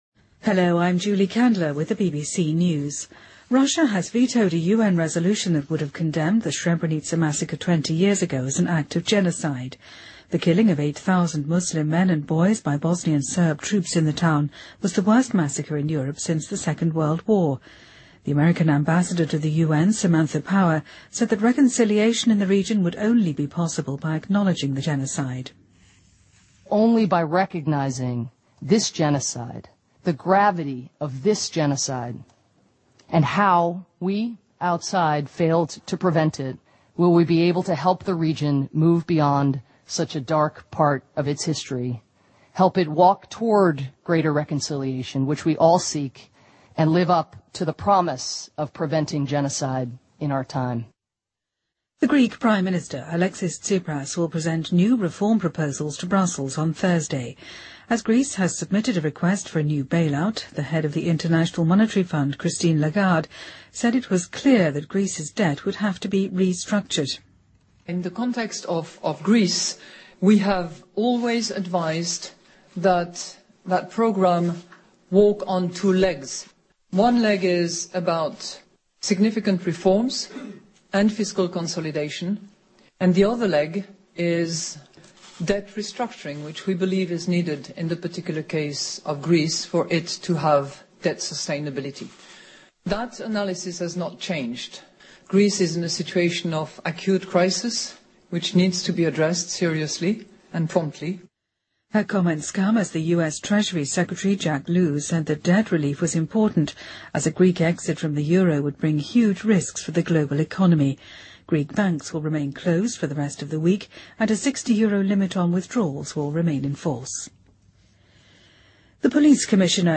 BBC news,美国南卡罗来纳州移除南方邦联旗帜